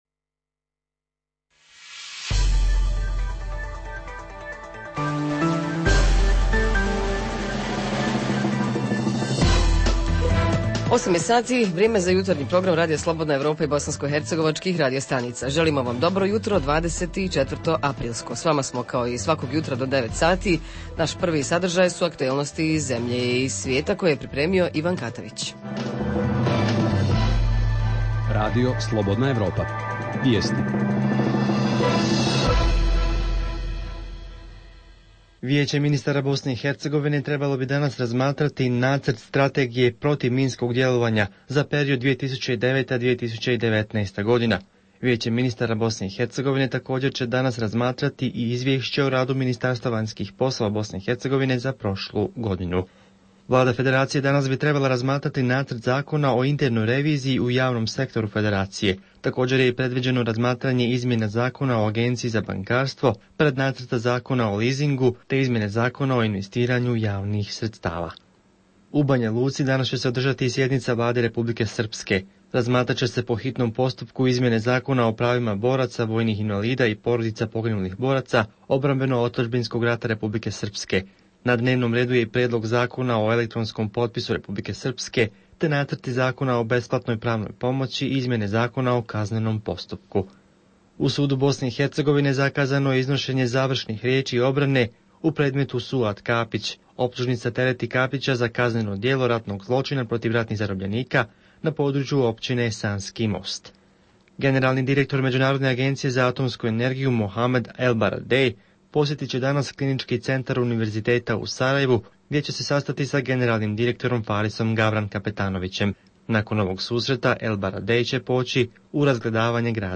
Jutarnji program za BiH koji se emituje uživo. Govorimo o sve češćim napadima na novinare.